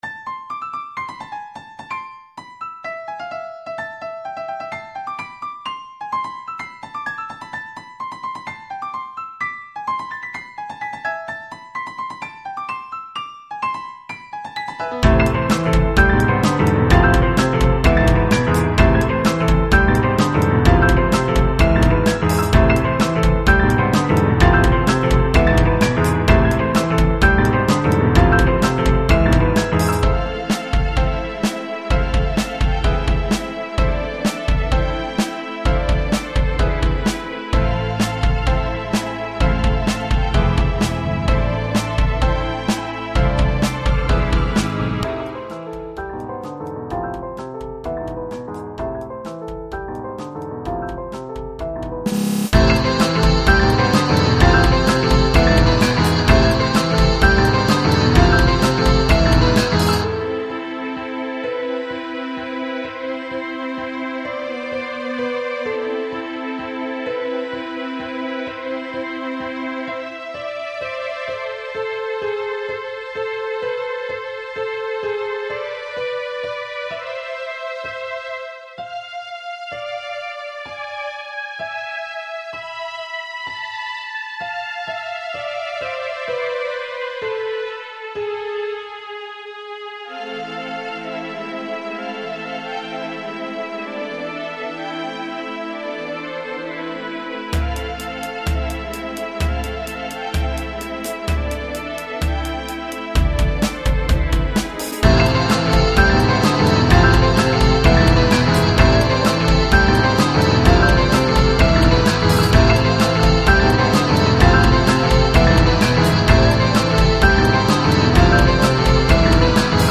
back to reality - Electronic - Young Composers Music Forum
Good news! messing around with piano patterns make a good song! sorry about the quality...